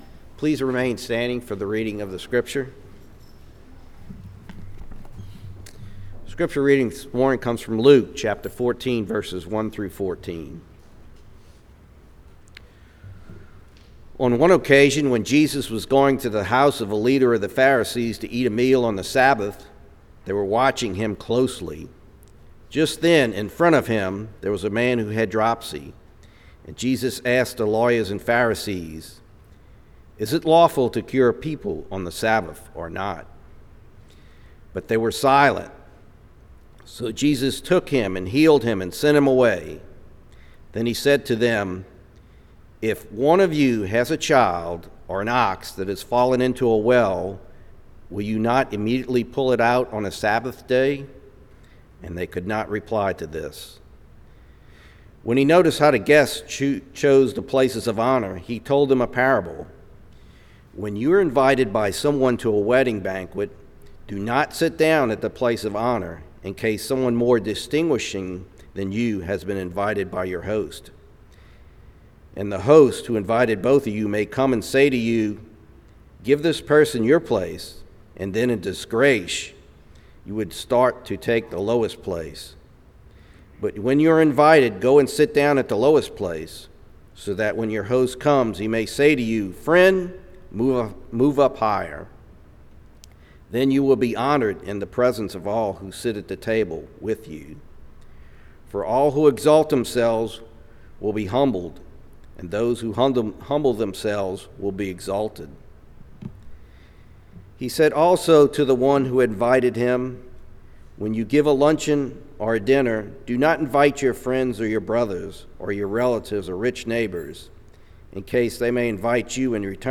St. Charles United Methodist Church Sermons